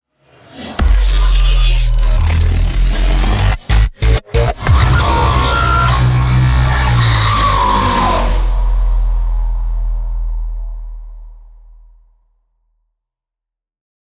На этой странице собраны звуки из вселенной Трансформеров: эффекты трансформации, футуристические боевые режимы, голоса известных автоботов и десептиконов.
Звуки трансформации перестрелки и другие эффекты из Трансформеров в mp3